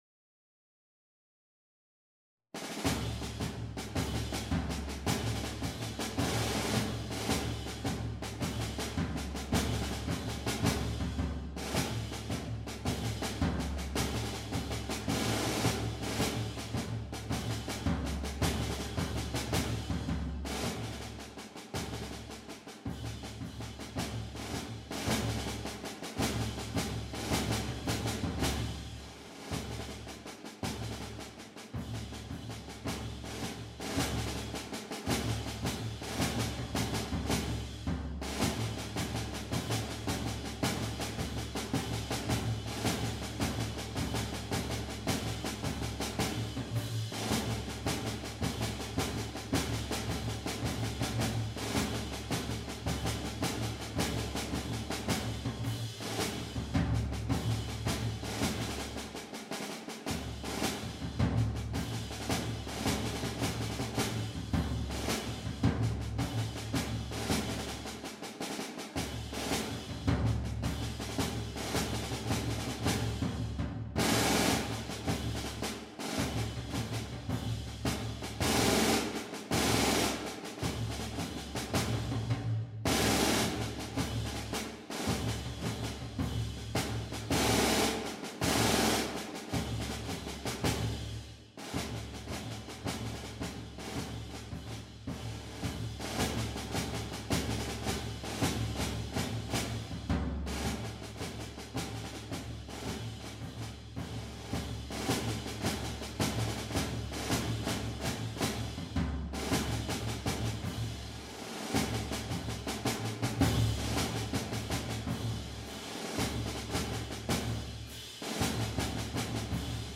2e Aantal Stemmen : 4 PDF